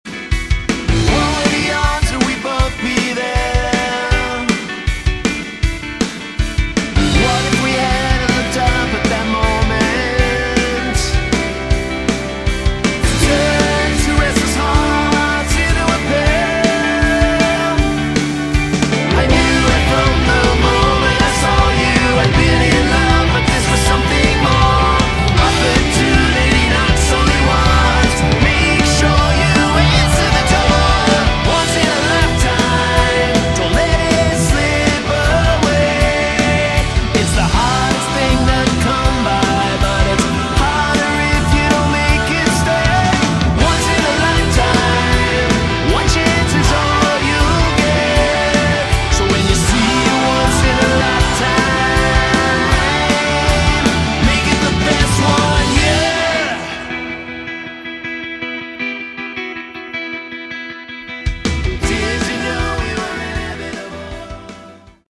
Category: Melodic Hard Rock
vocals
bass
guitars
keyboards
drums